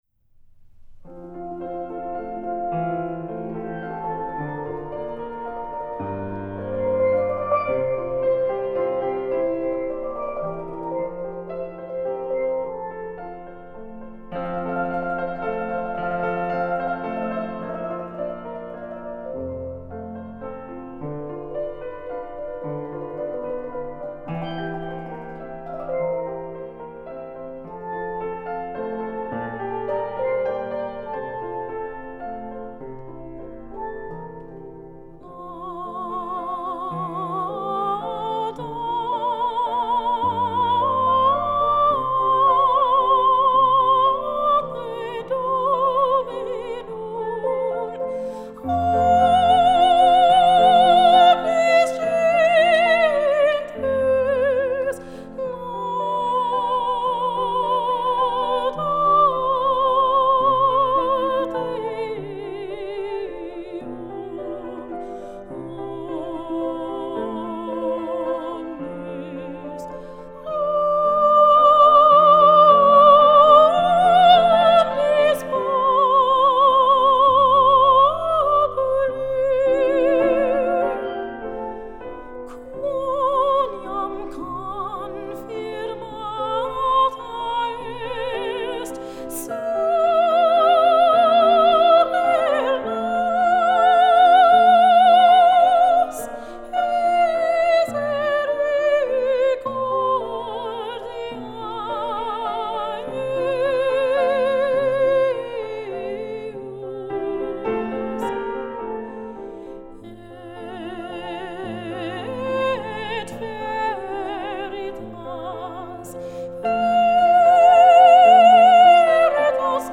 Classics with an Afrocentric flavour